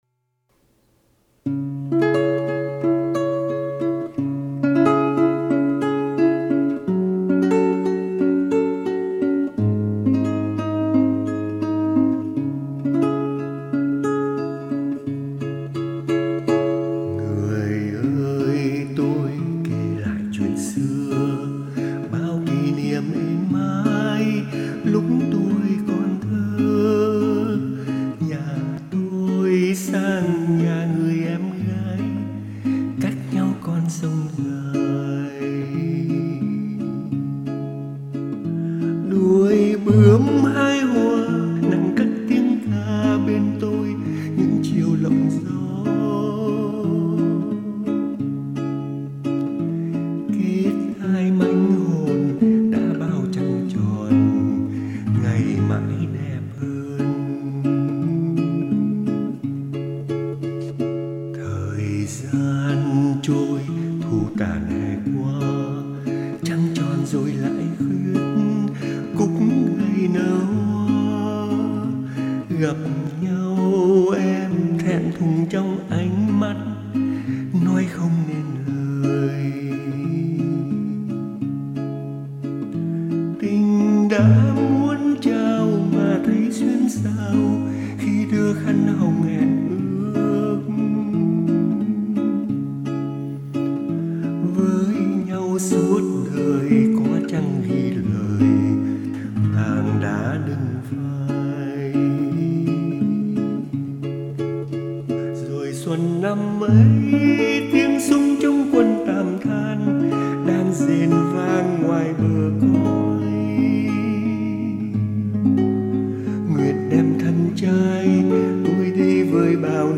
đàn và hát